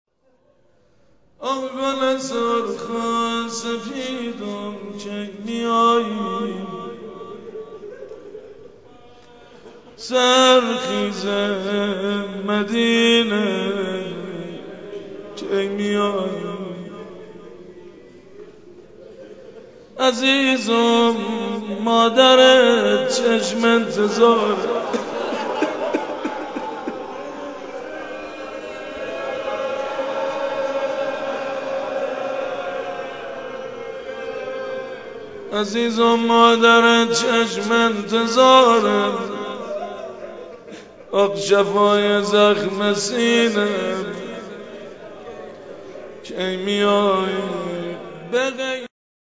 نوحه ی زیبا